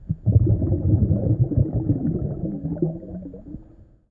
Water_Breath_02.mp3